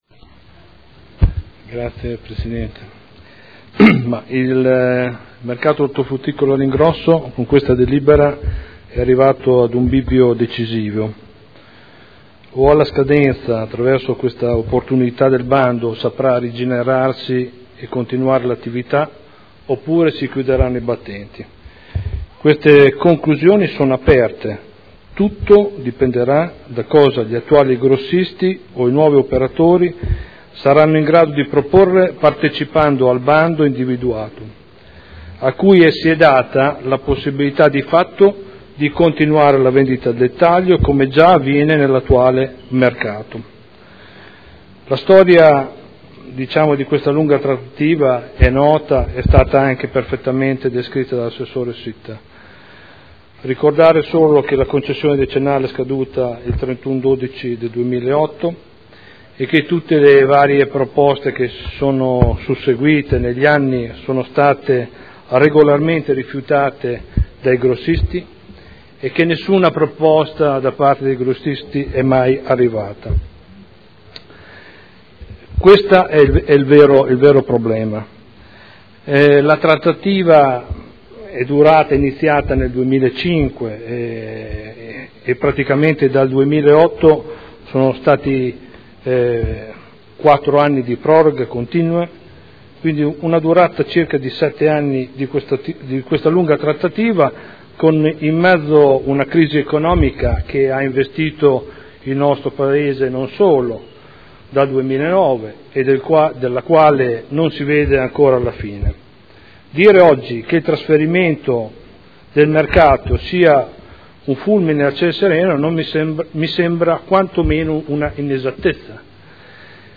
Seduta del 18/06/2012. Dibattito su proposta di deliberazione e ordine del giorno.